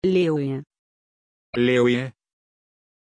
Pronunciation of Lewie
pronunciation-lewie-ru.mp3